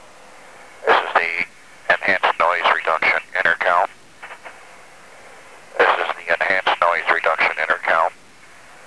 In flight audio output from the Enhanced Noise Reduction Intercom.
The recorded audio is audio from the intercom output.
The recorded audio is what is heard through the headphone's ear cup speaker.